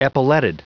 Prononciation du mot epauletted en anglais (fichier audio)